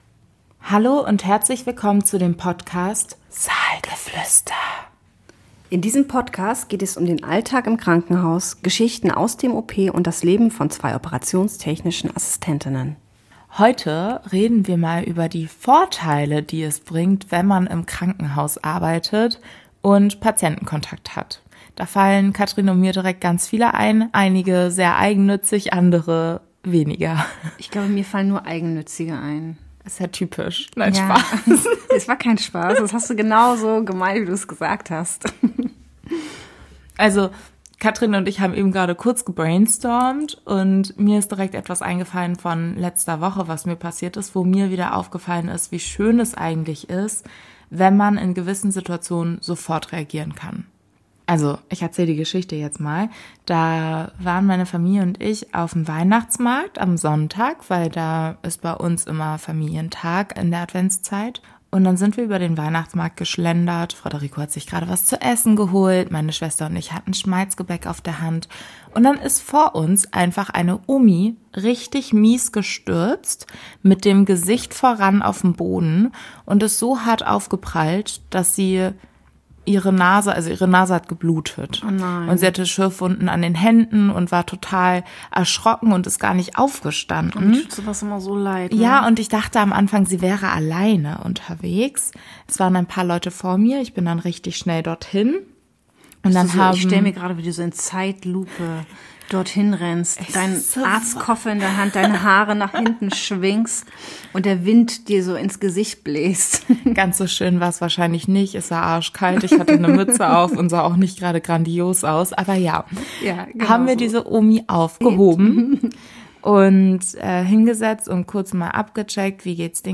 In dieser vorweihnachtlichen, gemütlichen Podcast-Folge plaudern wir darüber, warum sich der Job manchmal auch im Privatleben auszahlt.